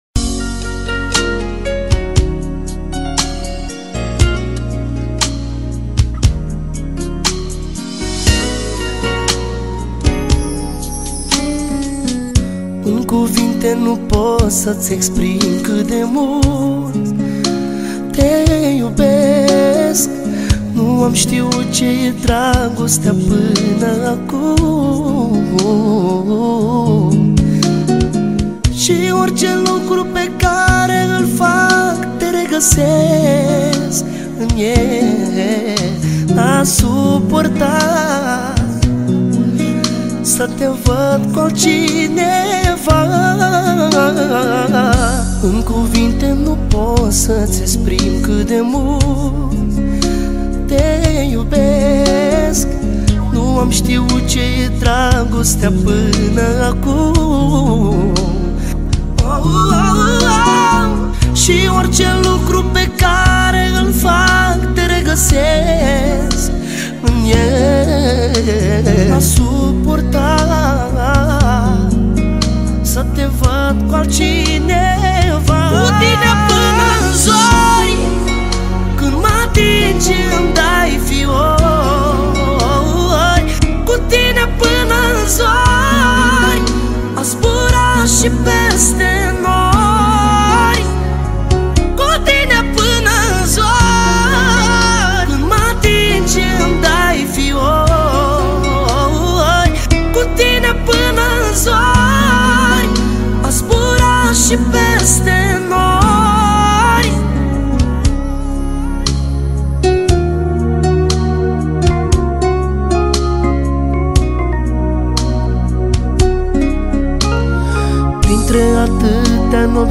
Data: 31.10.2024  Manele New-Live Hits: 0